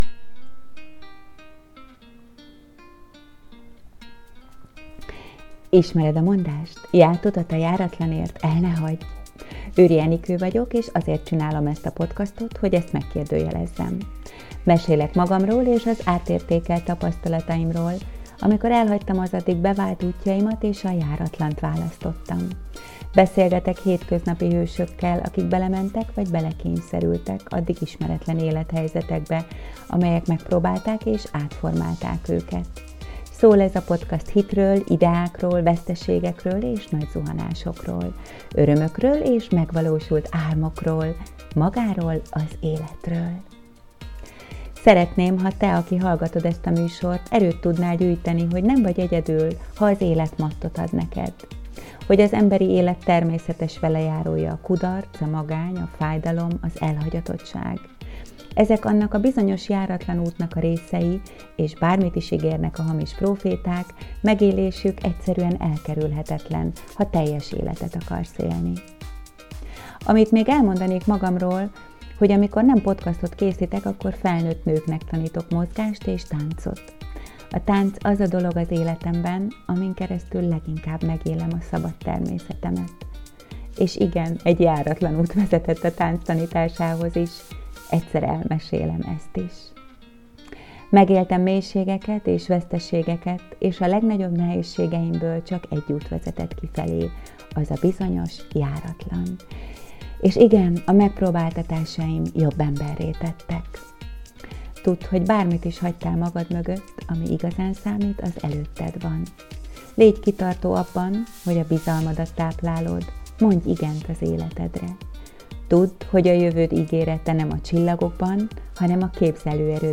Beszélgetek hétköznapi hősökkel, akik belementek, vagy belekényszerültek ismeretlen és félelmet keltő élethelyzetekbe, amelyek megpróbálták és átformálták őket. Szól ez a podcast hitről, ideákról, veszteségekről és nagy zuhanásokról.